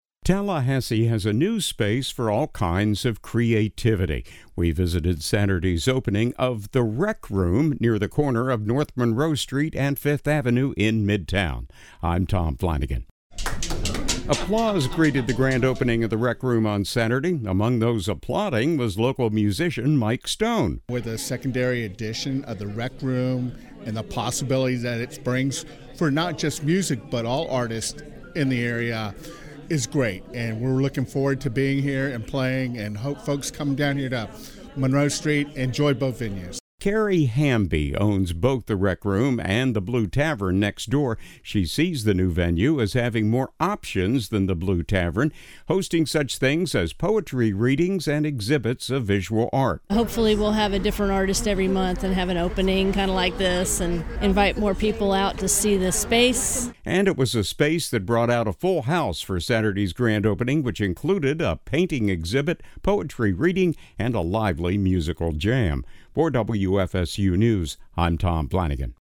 Tallahassee has a new space for all kinds of creativity. We visited Saturday’s opening of the “Req Room” (with “Req” pronounced as “Wreck”) near the corner of North Monroe Street and Fifth Avenue in Midtown.
Applause greeted the grand opening of the Req Room on Saturday.